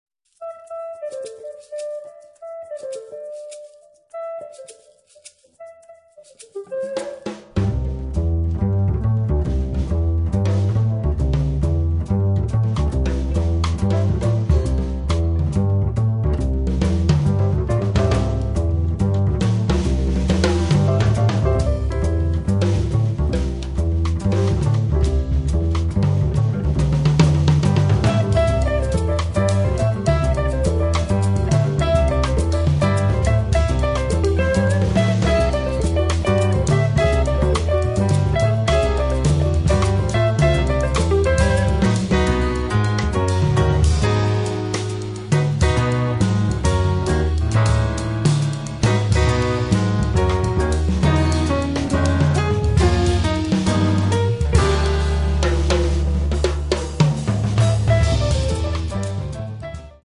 drums
guitar
piano
double bass
Il brano è un tessuto cool con tinte arabe.